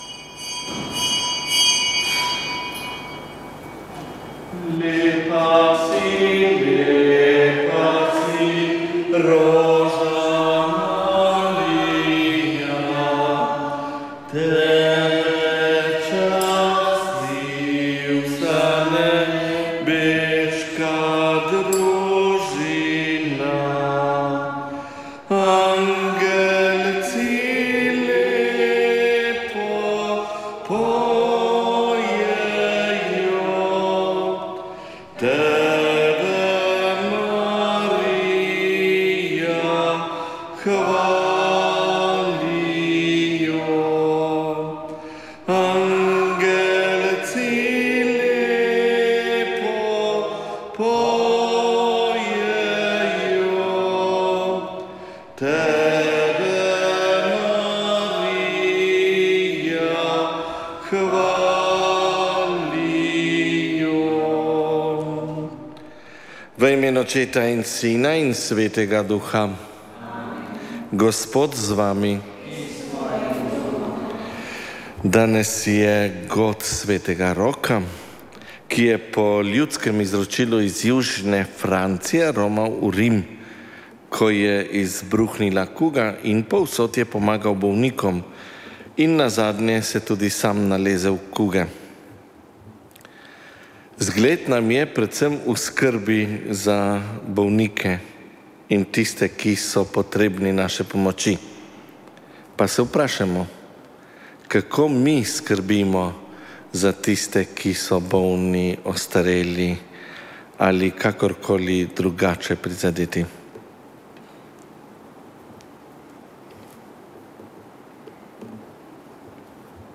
Sv. maša iz cerkve svetega Štefana v Vipavi 11. 5.
pevski zbor
na orgle